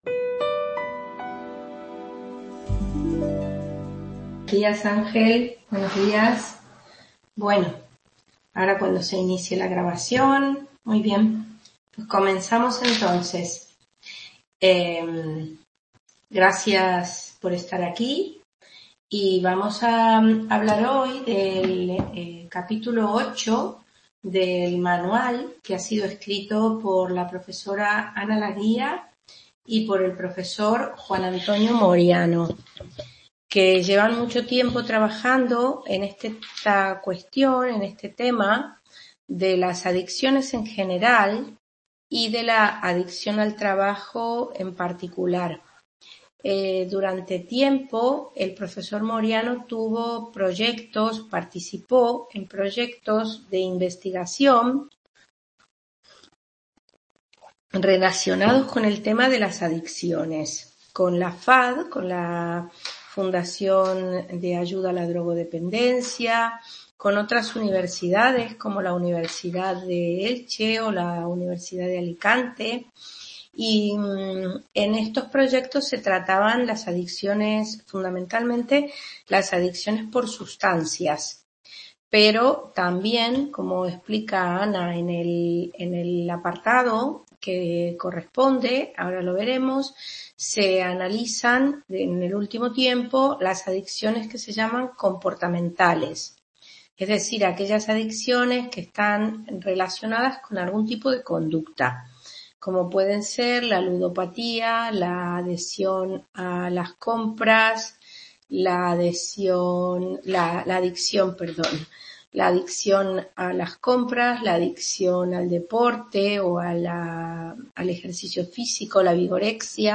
Grabación de la tutoría del Tema 8 del nuevo manual de Psicología del Trabajo